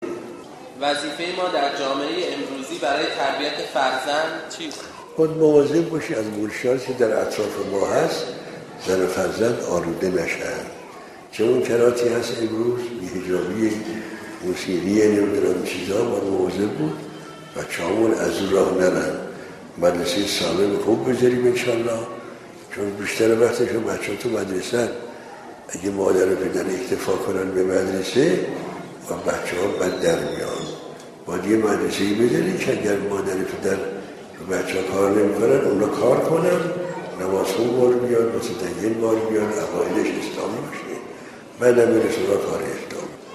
پرسش و پاسخی